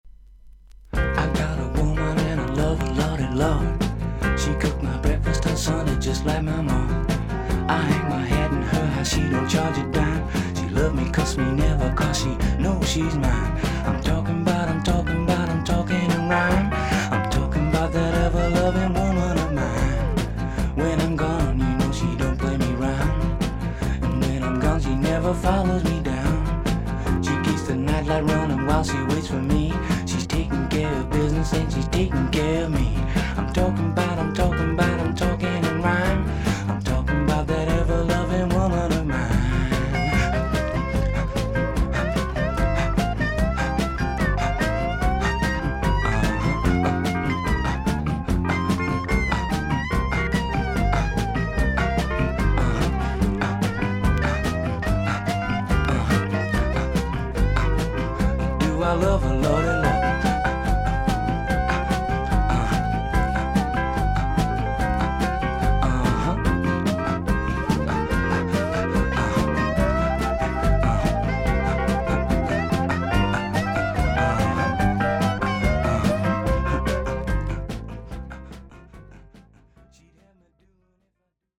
少々軽いパチノイズの箇所あり。クリアな音です。A面に長いスジが見えますが浅いので音に出ません。
イギリスのモッズ/オルガン奏者/シンガー。